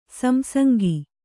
♪ samsangi